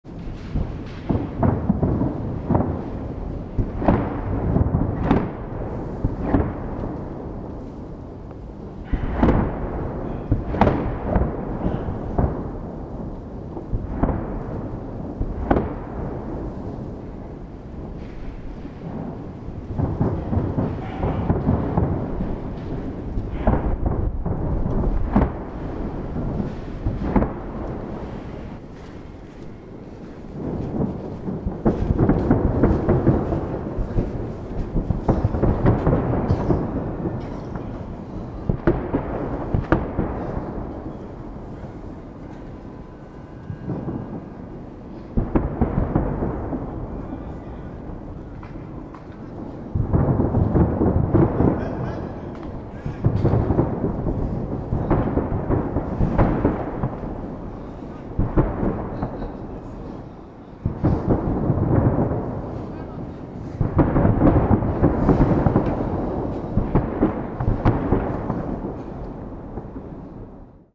звучит салют впечатляюще!